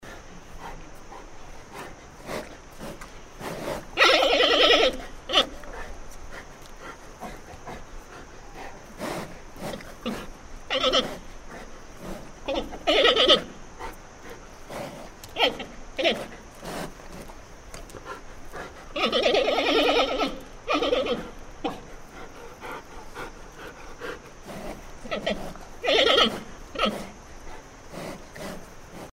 Звуки панды
Звук дыхания и блеяние панды